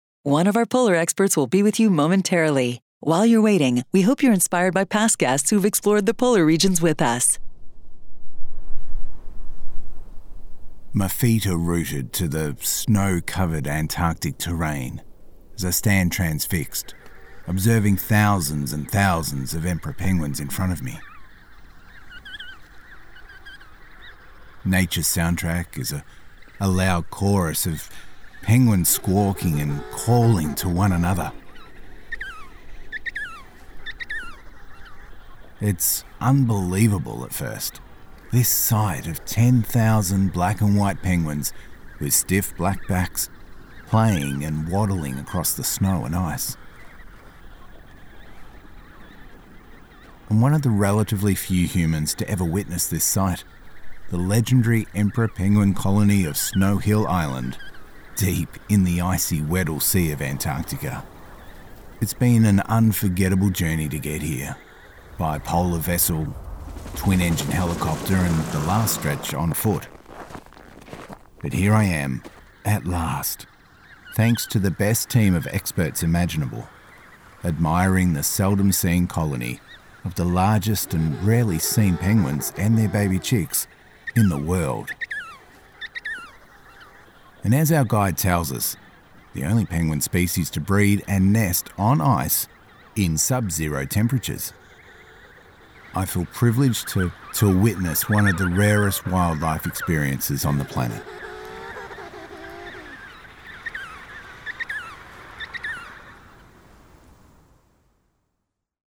English - Australian
Middle Aged